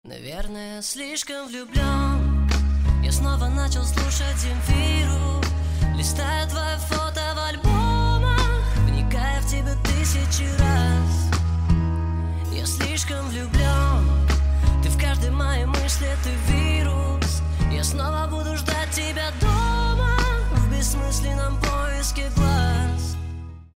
Спокойные И Тихие Рингтоны
Рок Металл Рингтоны